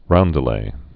(roundə-lā)